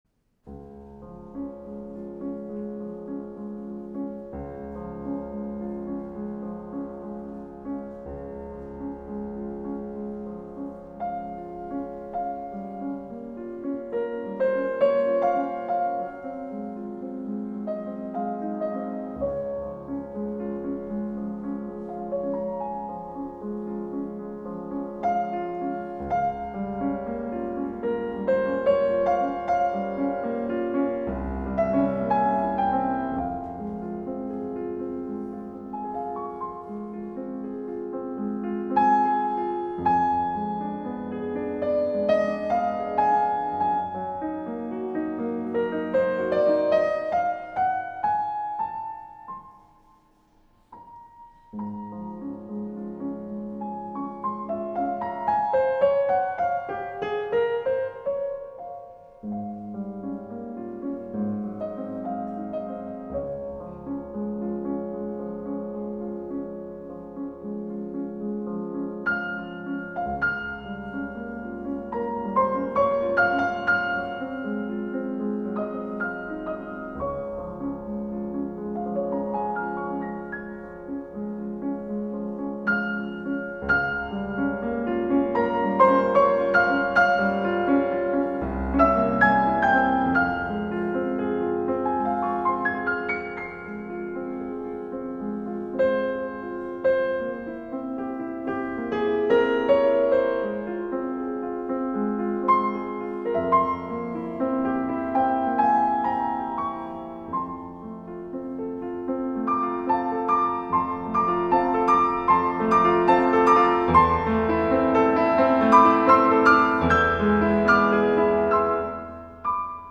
Трек размещён в разделе Зарубежная музыка / Классика.